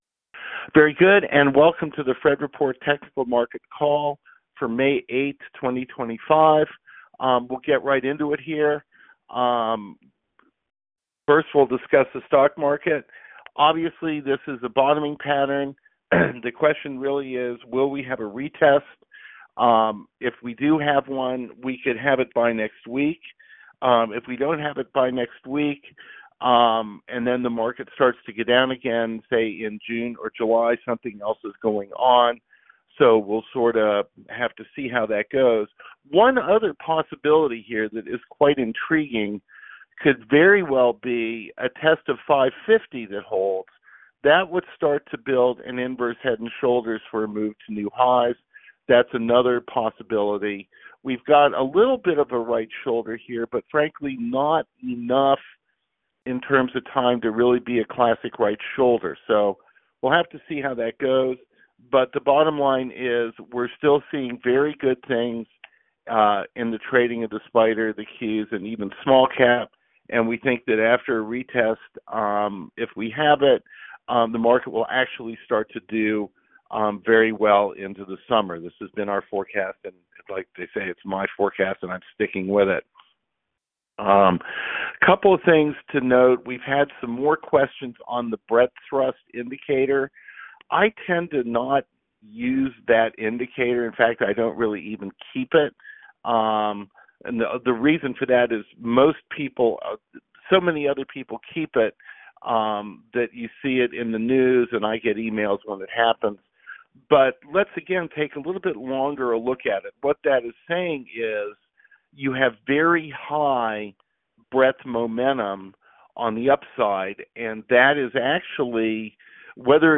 The Fred Report - Conference Call May 8, 2025